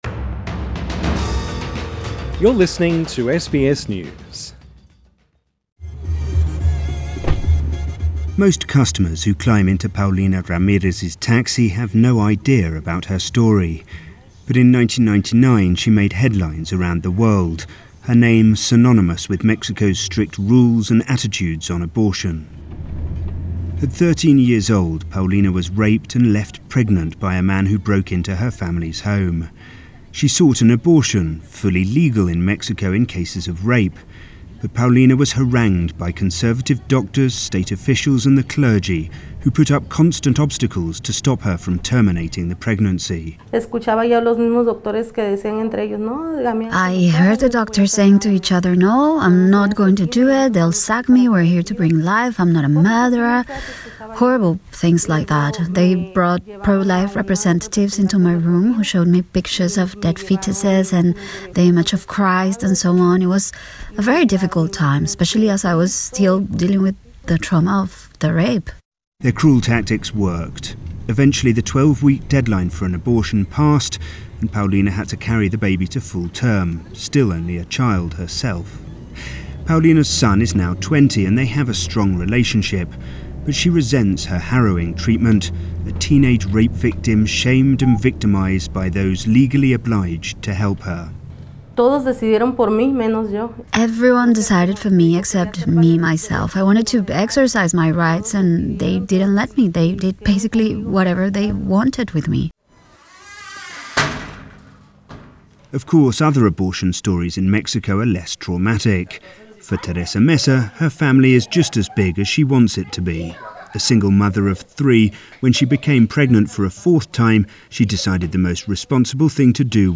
This story was first broadcast on the BBC World Service Share